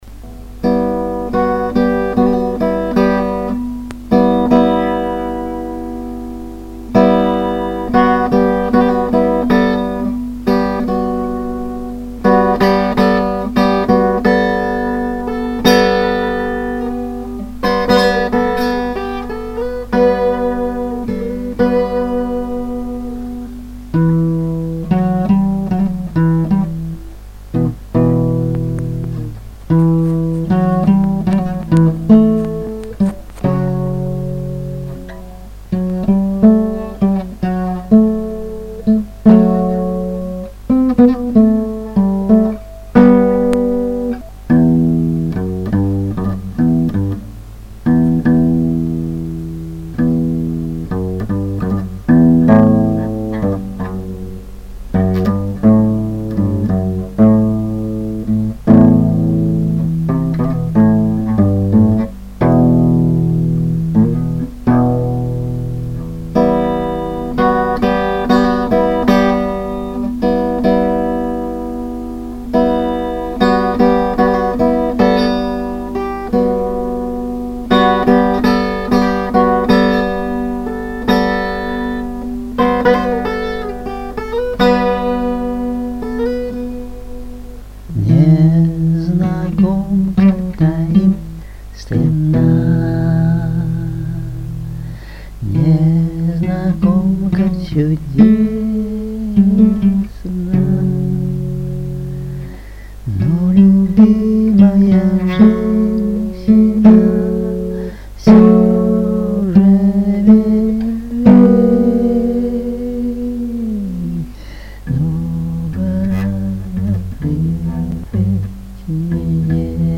песня
Рубрика: Поезія, Авторська пісня